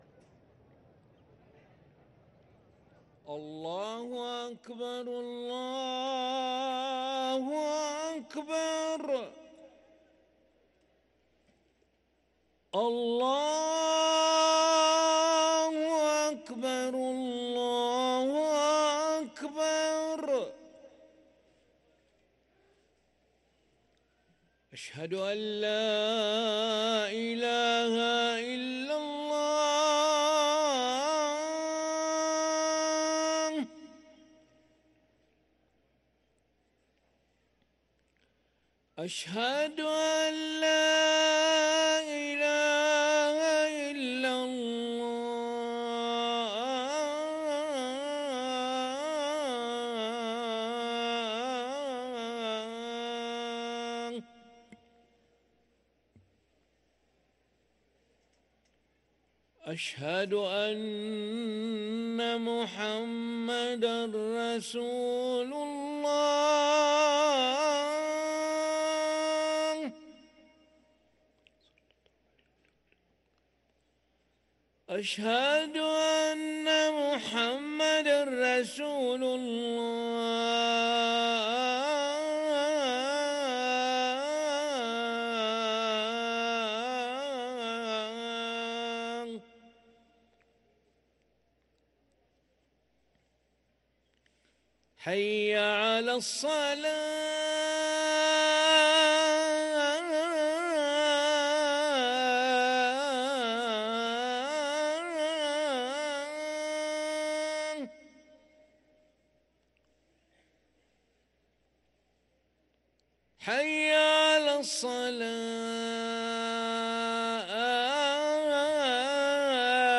أذان العشاء للمؤذن علي ملا الأحد 29 جمادى الآخرة 1444هـ > ١٤٤٤ 🕋 > ركن الأذان 🕋 > المزيد - تلاوات الحرمين